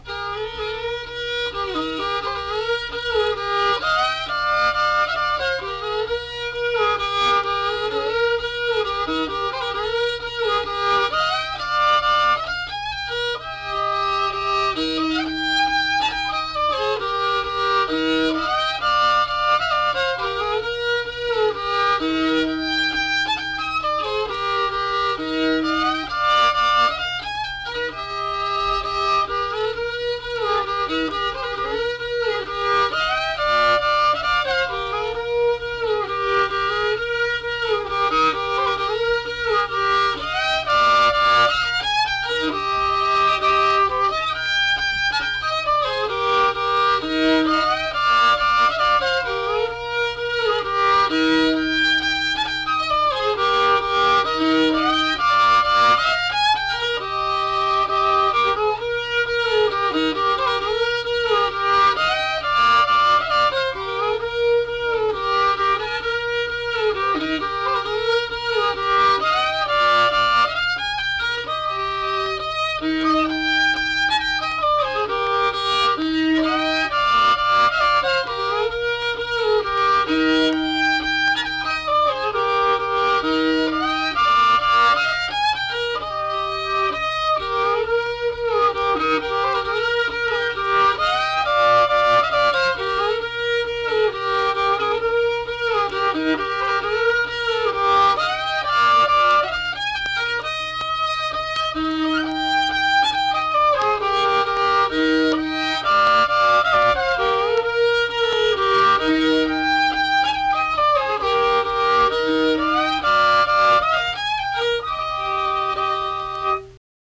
Schottische
Unaccompanied fiddle music and accompanied (guitar) vocal music performance
Instrumental Music
Fiddle